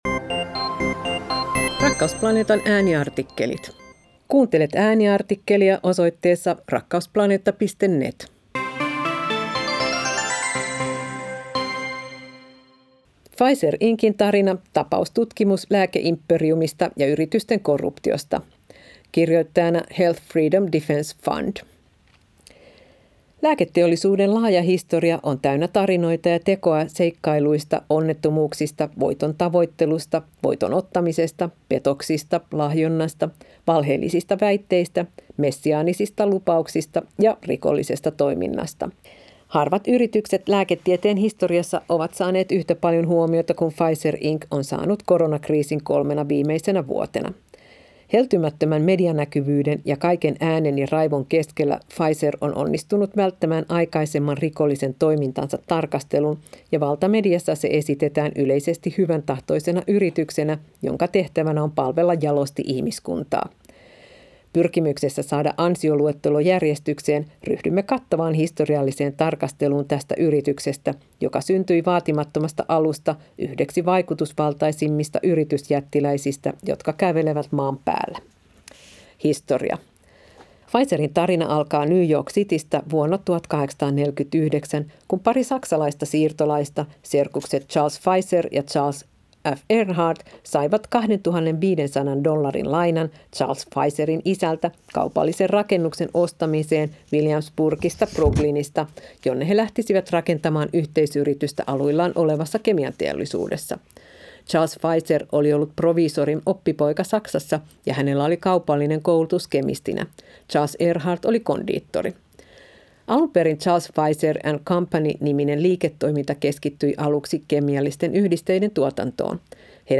Ääniartikkelit